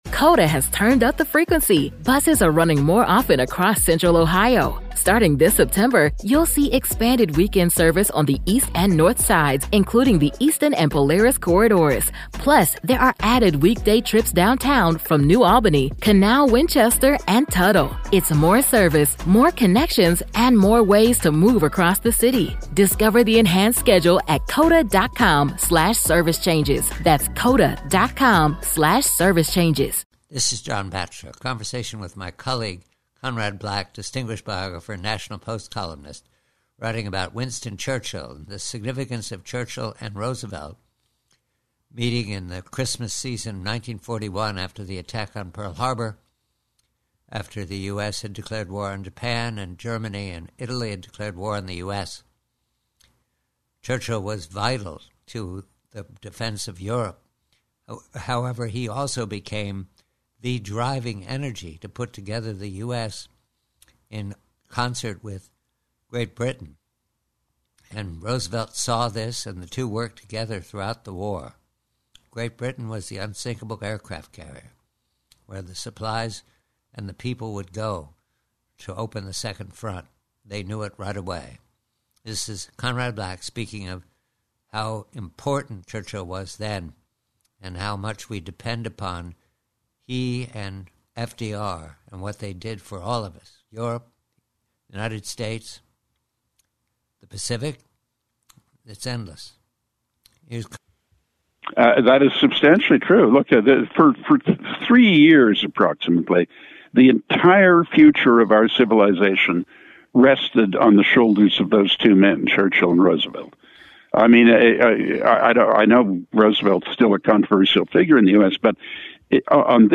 Headliner Embed Embed code See more options Share Facebook X Subscribe PREVIEW: WINSTON CHURCHILL: FDR: CHRISTMAS 1941 Conversation with colleague Conrad Black, writing in the National Post, regarding the profound significance of how Churchill and FDR worked together to defeat the Hitlerites and the Japanese Empire -- how vital Churchill was to rallying the US and Canada in the bleak days of '41-'43.